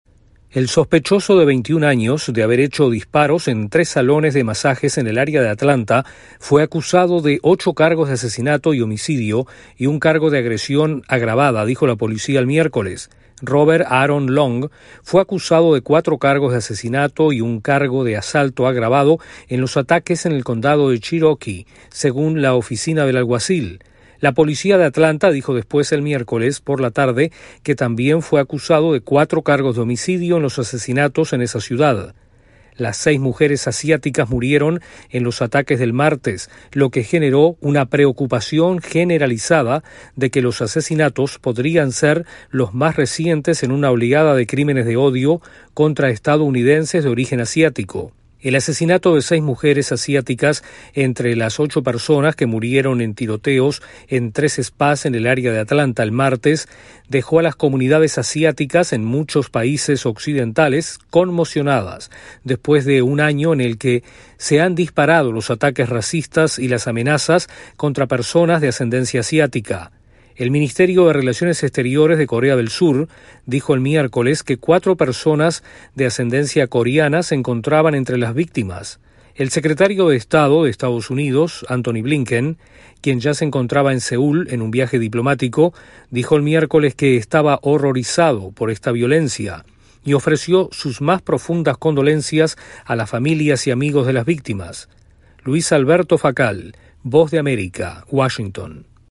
Arrestan en Atlanta, Georgia, al sospechoso de ocho asesinatos en salones de masajes. Entre las víctimas hay seis mujeres asiáticas. Informa